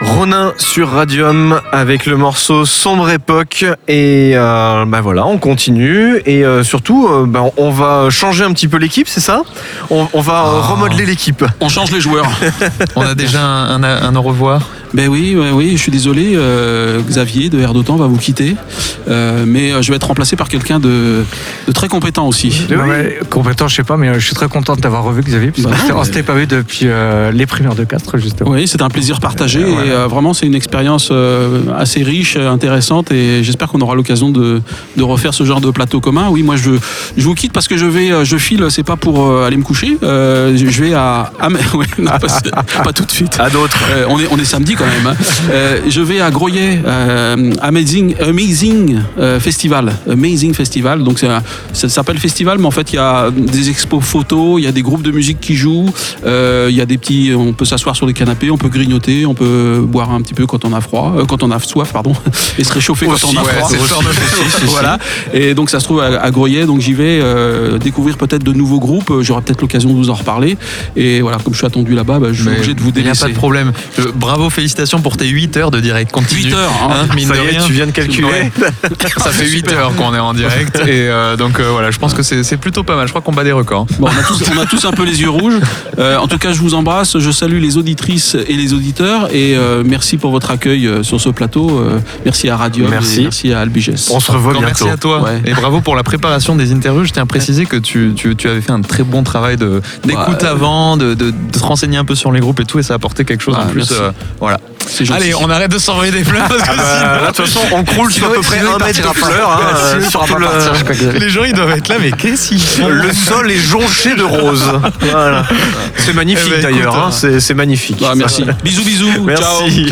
Après leur showcase, RUMPUS débarque en nombre sur le plateau des radios, nous rappelle sa prononciation exacte, et nous épate une fois de plus à la lecture de leur parcours atypique.
Identification